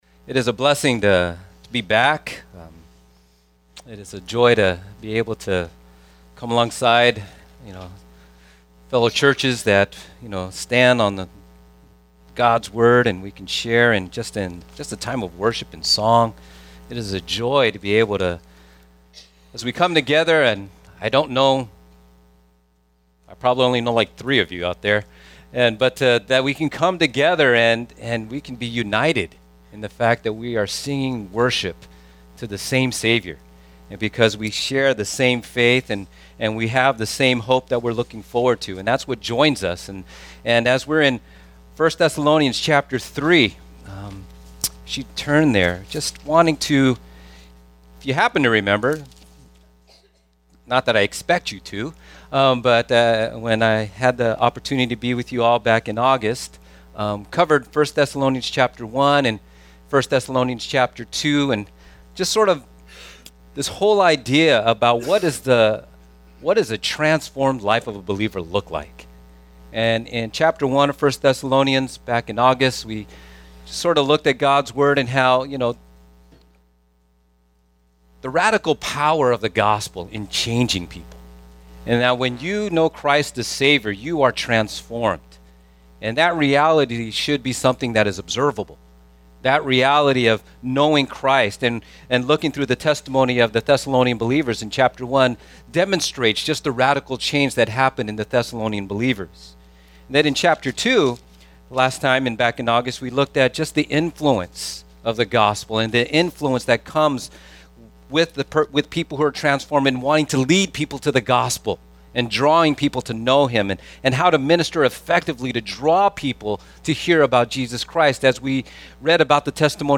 The Compassion Born Out of a Transformed Life Guest Preacher